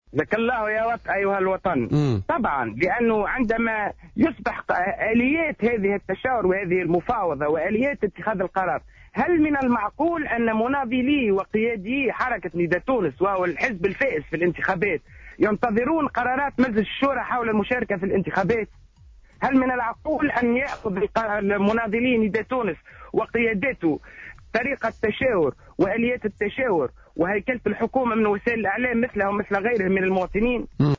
Dans son intervention sur les ondes de Jawhara FM dans le cadre de l’émission Politica du jeudi 22 janvier 2015